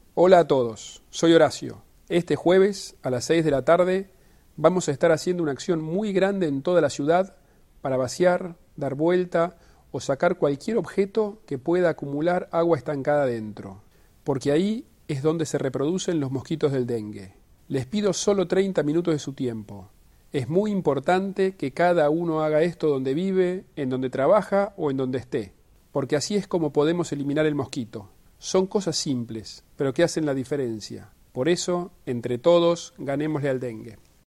El jefe de Gobierno, Horacio Rodríguez Larreta, grabó un mensaje sobre los cuidados que los vecinos deben tener en cuenta para prevenir el dengue.